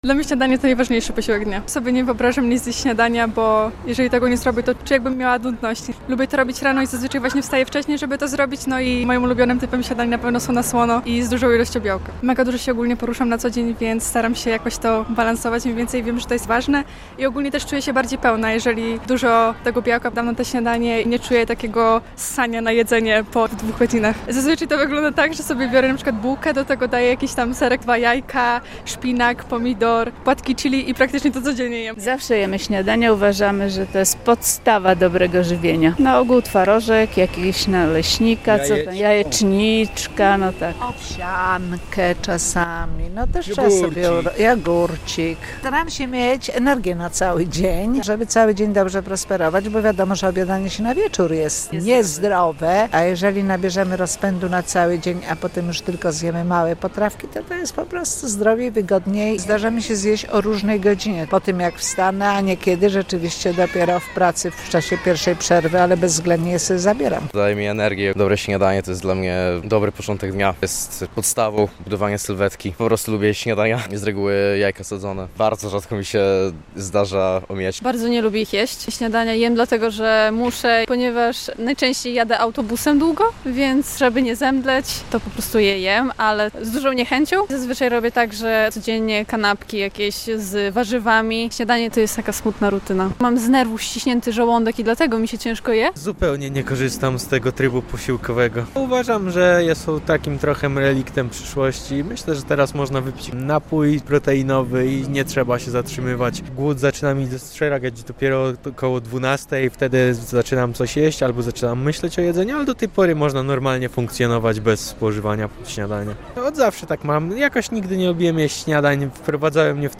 Kanapki, jajka, naleśniki, czy sama kawa - białostoczanie mówią, jak wygląda ich podejście do pierwszego posiłku dnia.